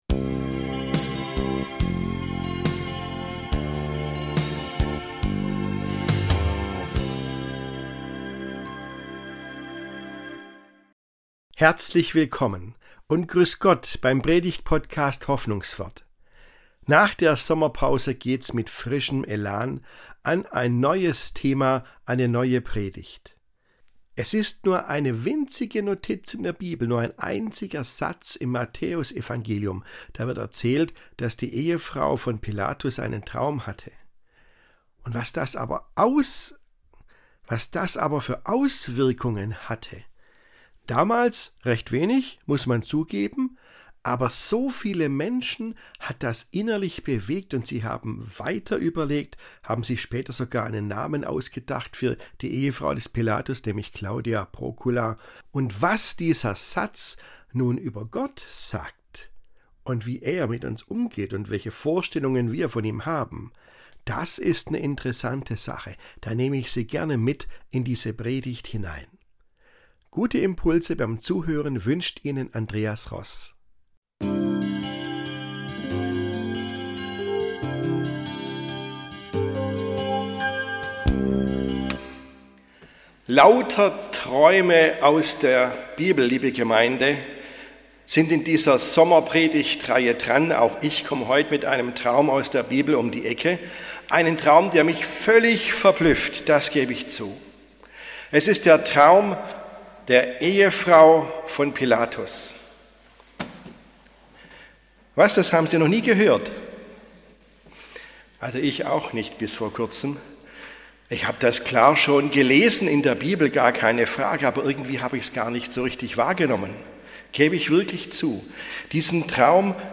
Und kratzt an allzu festgefahrenen Vorstellungen von Gott. Diese Predigt ist Teil der Sommerpredigtreihe 2025.